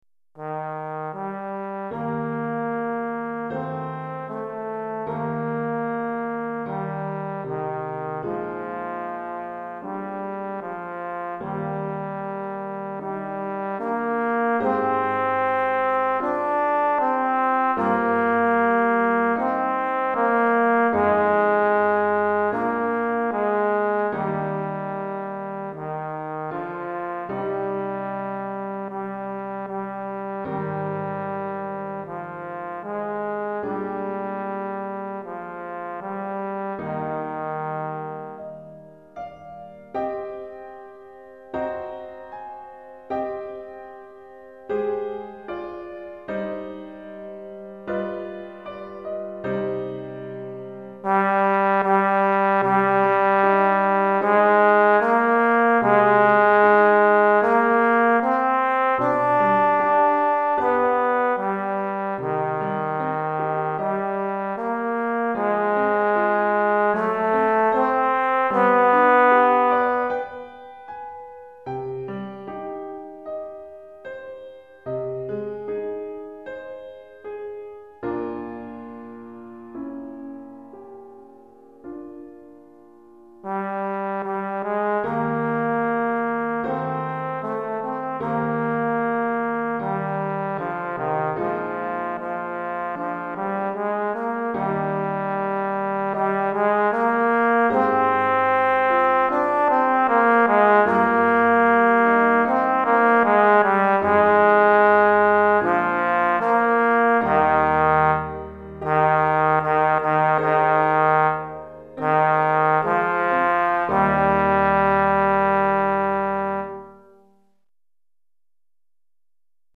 Pour trombone et piano DEGRE CYCLE 1